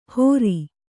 ♪ hōri